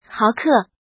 怎么读
háokè